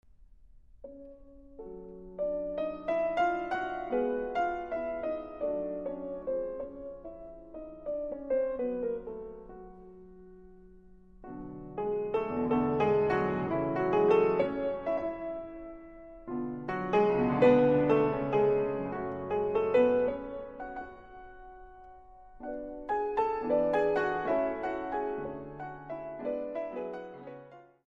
〜ローズウッドの音をもう一度！〜
1887年製ピアノの音が鳴り響きます。